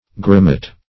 gromet - definition of gromet - synonyms, pronunciation, spelling from Free Dictionary Search Result for " gromet" : The Collaborative International Dictionary of English v.0.48: Gromet \Grom"et\ (gr[o^]m"[e^]t), n. Same as Grommet .